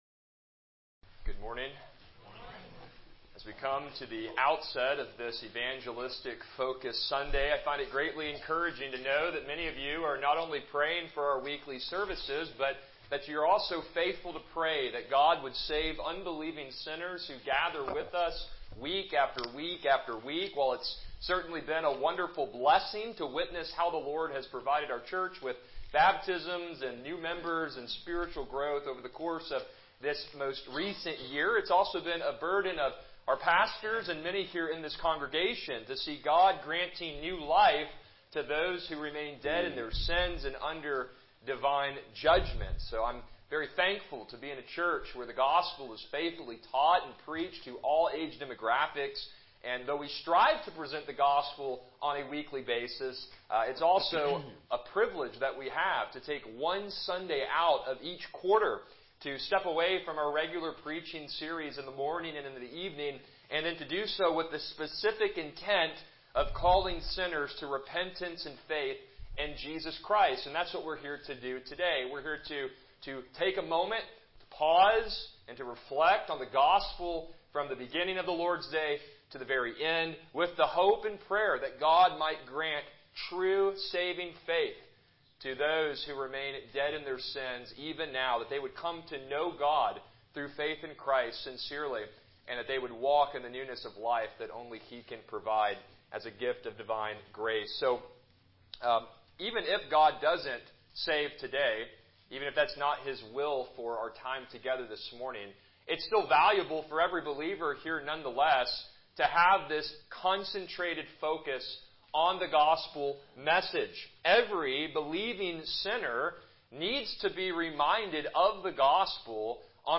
Passage: Luke 13:1-5 Service Type: Morning Worship